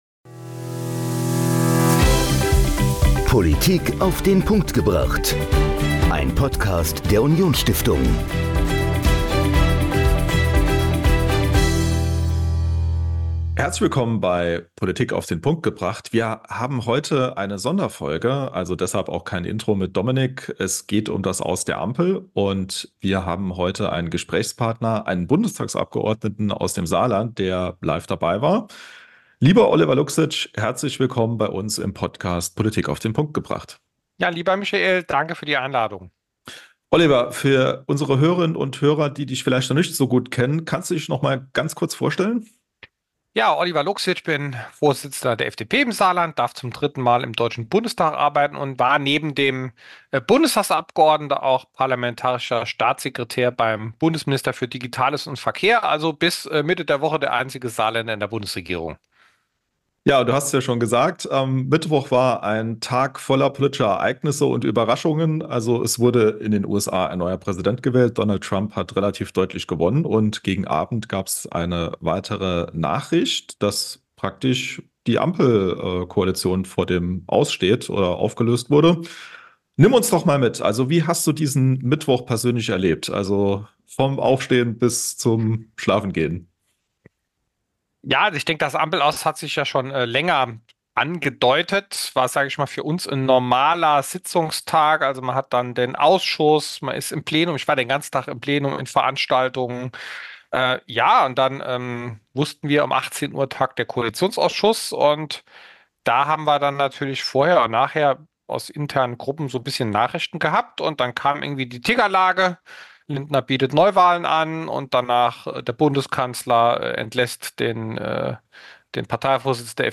Politik auf den Punkt gebracht - der Podcast der Union Stiftung liefert Dir neue Ideen aus den Bereichen Politik, Wirtschaft, Gesellschaft und Kultur. In regelmäßigen Folgen stellen wir Euch inspirierende Interviewpartner vor, die bei uns zu Gast waren.